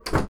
CloseBox.wav